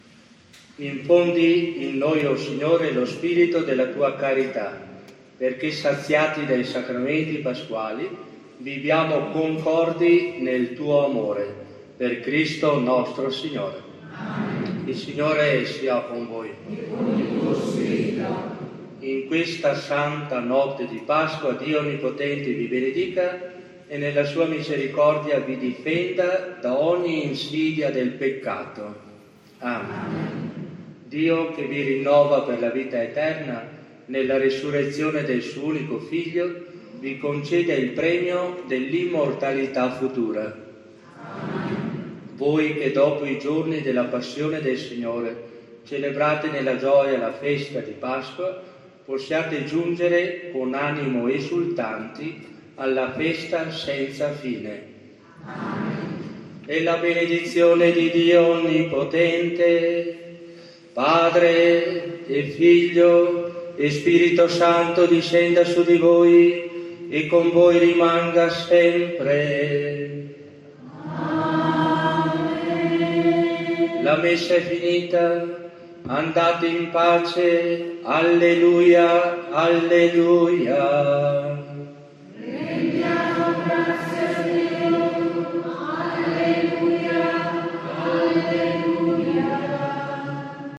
Benedizione pasquale